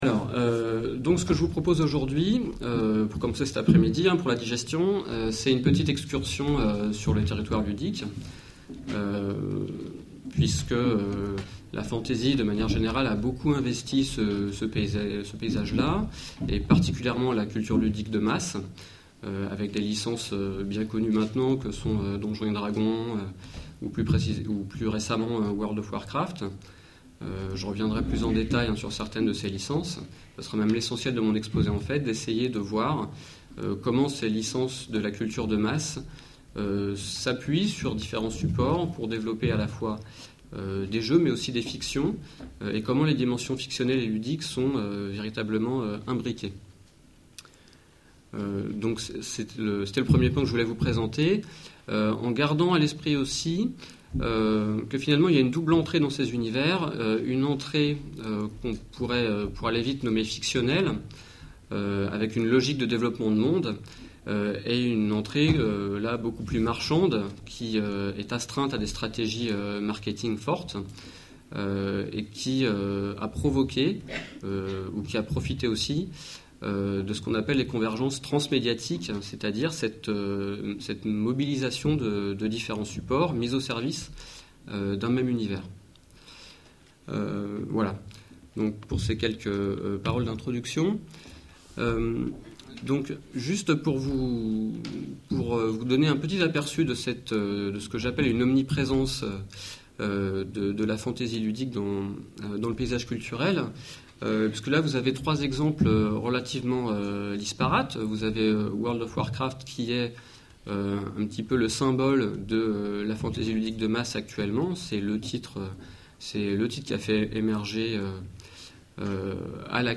En mars dernier, l’association organisait une journée d’étude autour de la fantasy.